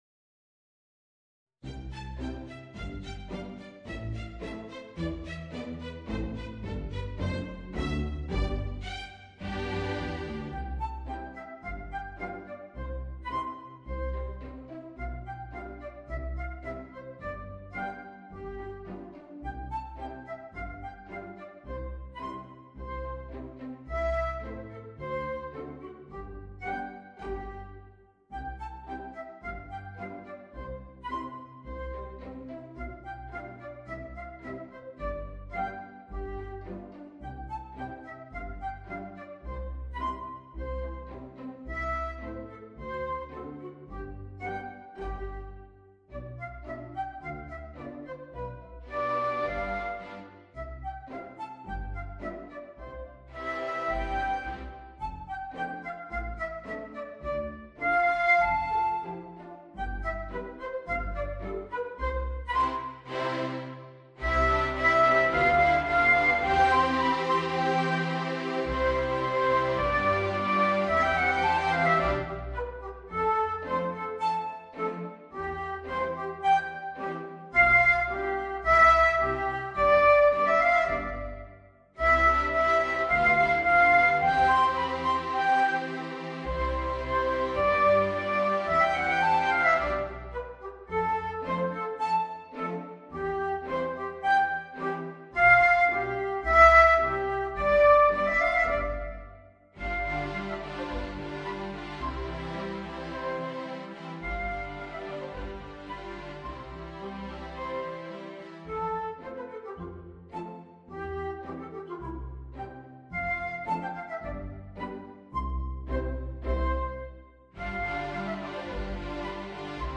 Voicing: Tuba and String Orchestra